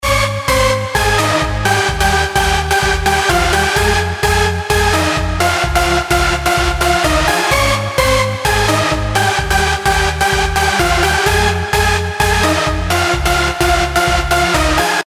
Он такой мощный!